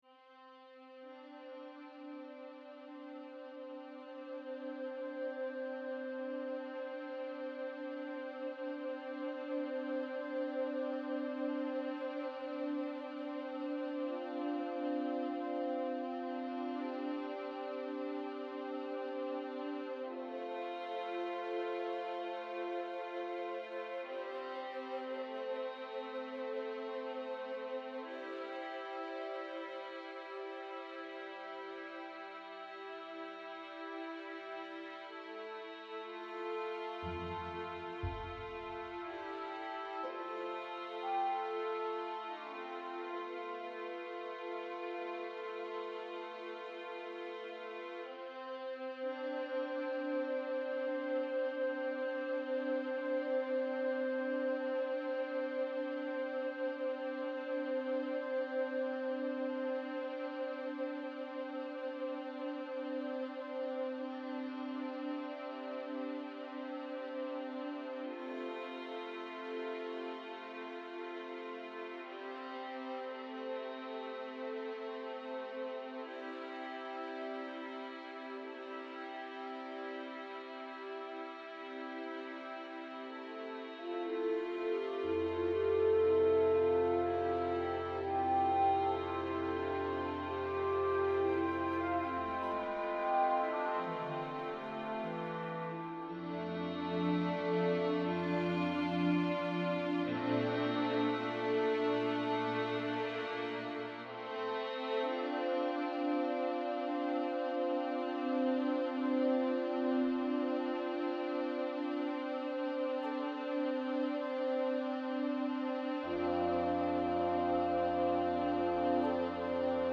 per pianoforte e orchestra